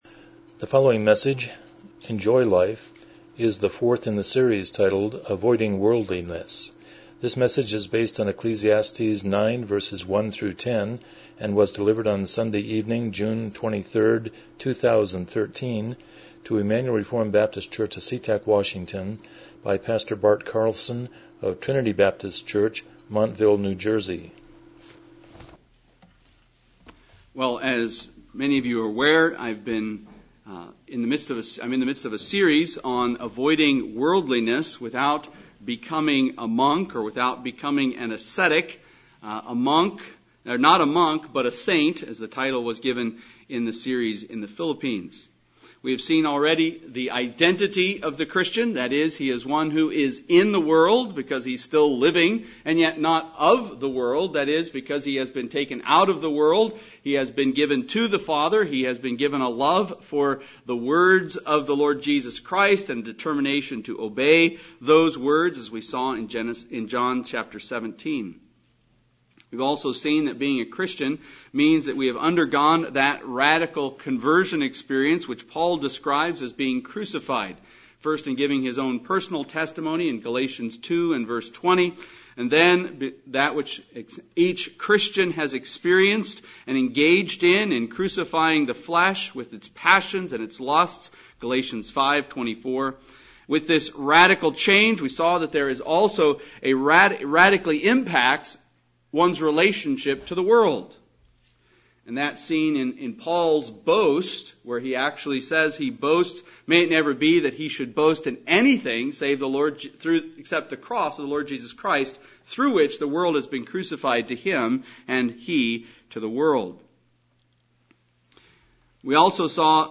Passage: Ecclesiastes 9:1-10 Service Type: Evening Worship